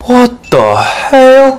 What The Hell (loud)